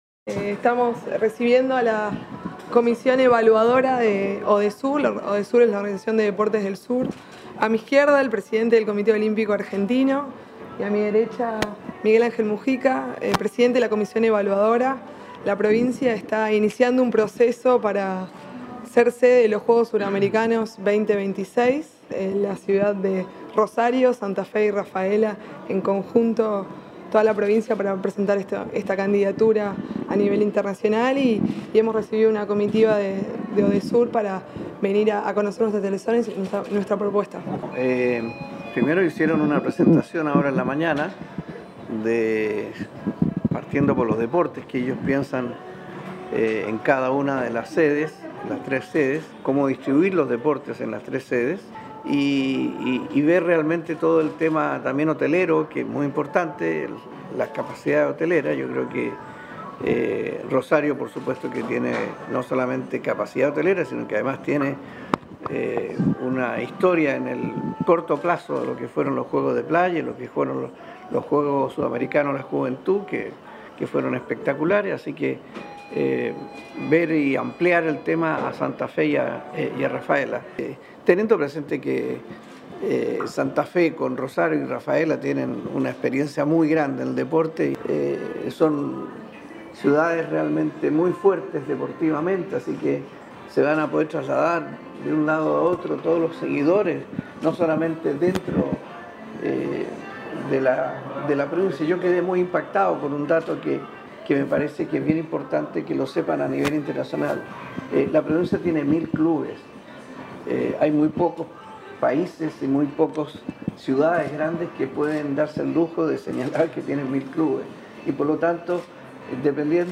En ese marco, el Presidente de la Comisión Evaluadora de ODESUR, Miguel Angel Mujica; el Presidente del Comité Olímpico Argentino -COA-, Mario Moccia; y la Secretaria de Deportes de la Provincia, Florencia Molinero, atendieron a la prensa luego de un primer encuentro donde la funcionaria provincial presentó el dossier de candidatura de la Provincia de Santa Fe.